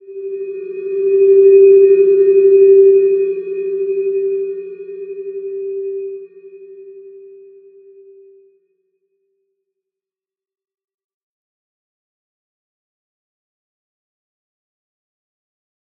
Slow-Distant-Chime-G4-mf.wav